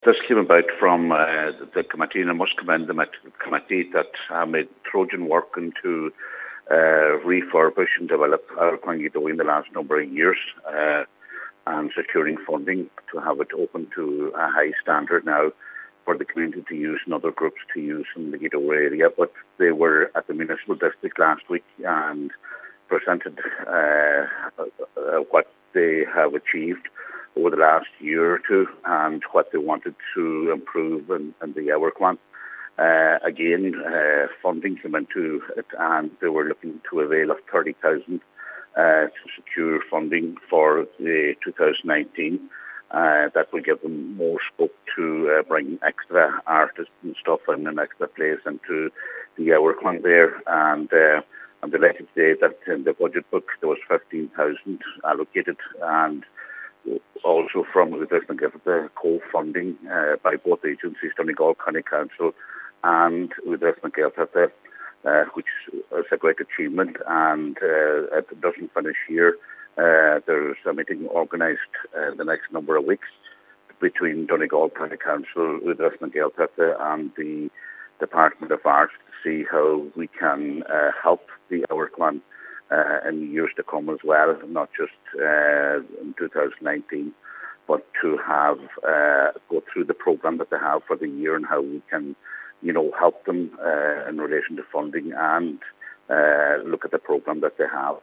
Cathaoirleach of the Glenties Municipal District Councillor John Sheamais Ó Fearraigh says the funding is a huge boost for the theatre and will pave the way for further investment: